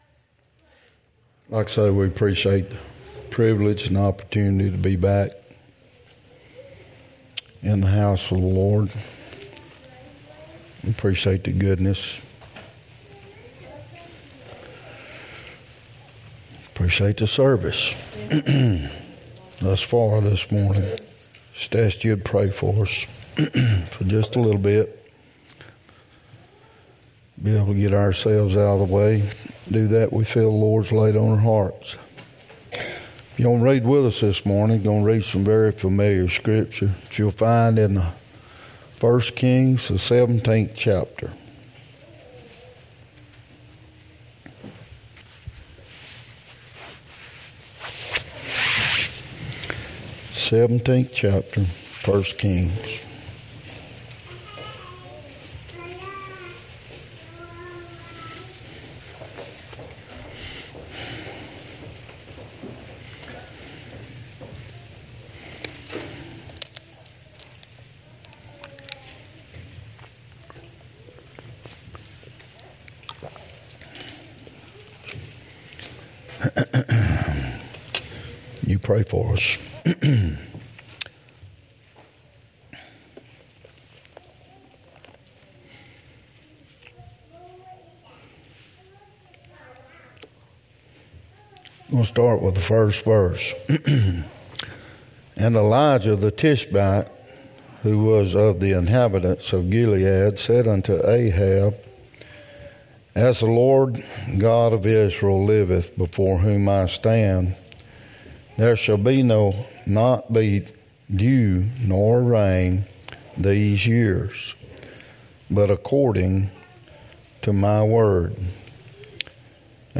Service Type: Wednesday night